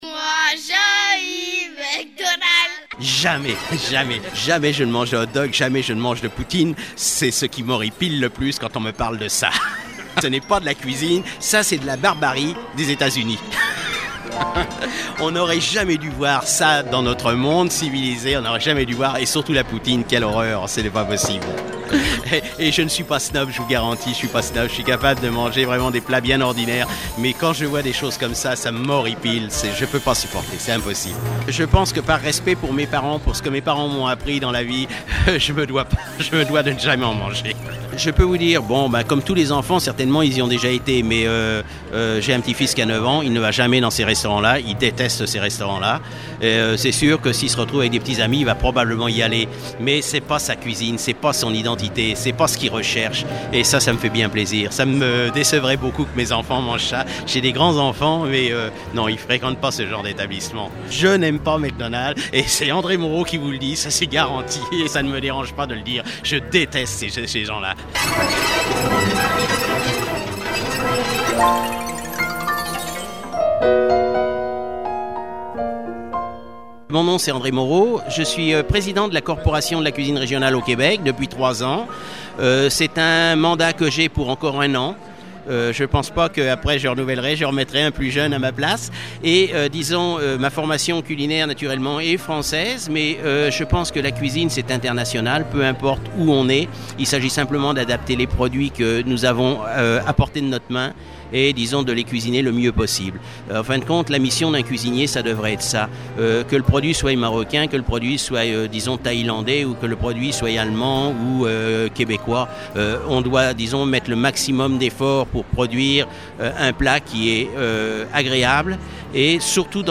Reportage
C'est au Salon du livre de Montréal que j'ai rencontré par hasard un cuisinier français vivant au Québec. Rencontre avec un cuisinier du terroire.